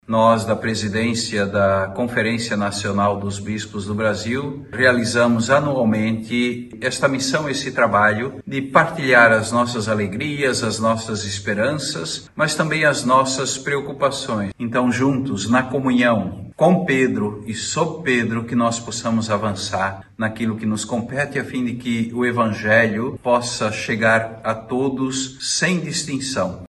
Essas visitas visaram fortalecer os laços entre a Igreja no Brasil e a Santa Sé, além de promover a troca de experiências e aprofundar a compreensão mútua, como explica o Cardeal Spengler.